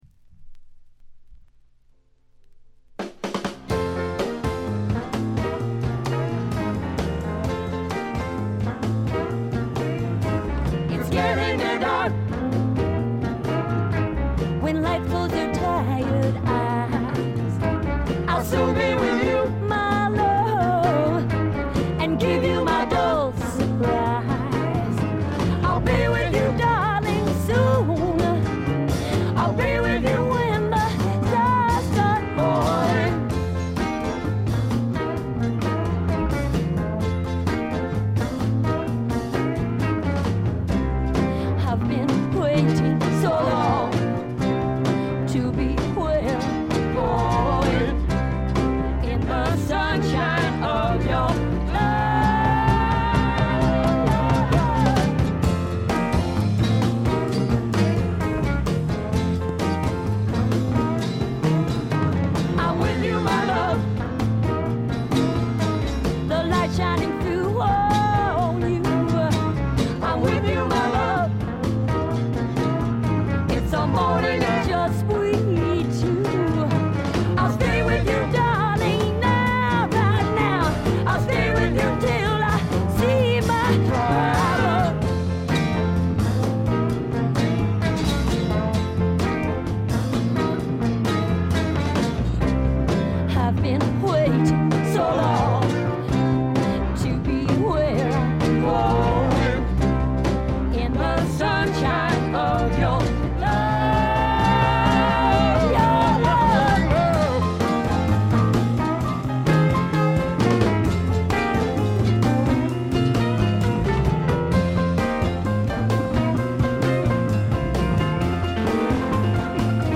ところどころでチリプチ、プツ音。
試聴曲は現品からの取り込み音源です。